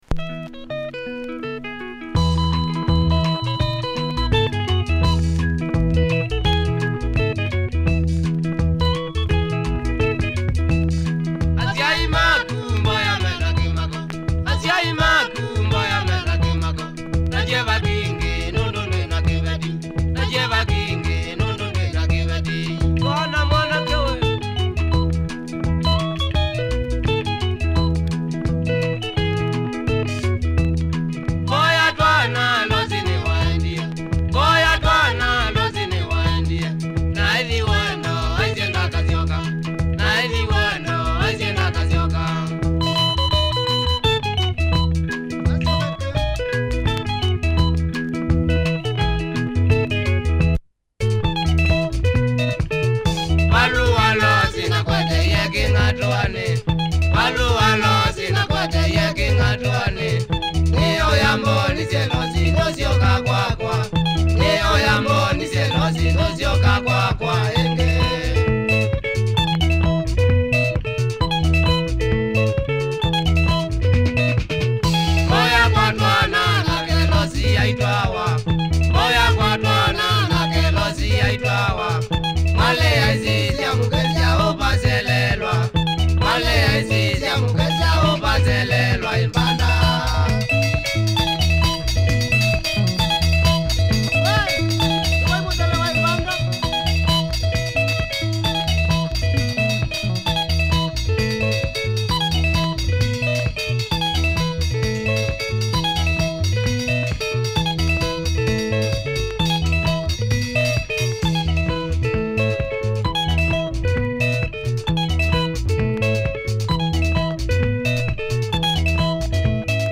Good tempo Kamba benga, check audio of both sides!